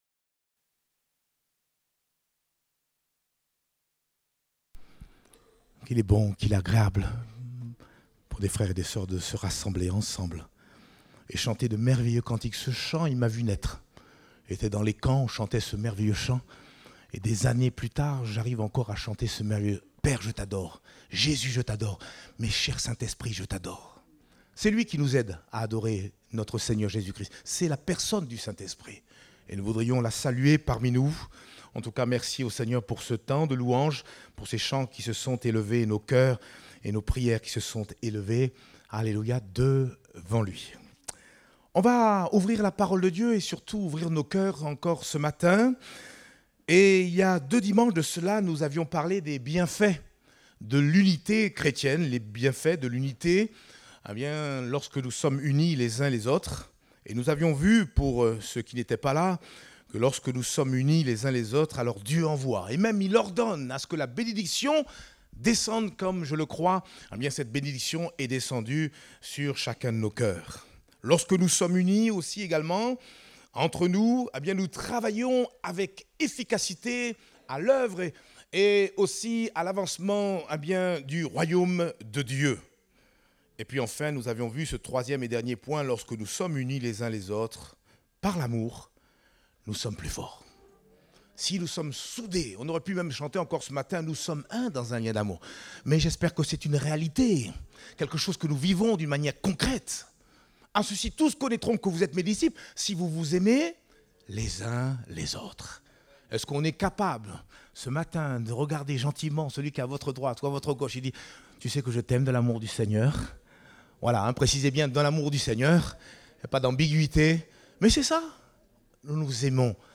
Date : 3 mars 2024 (Culte Dominical)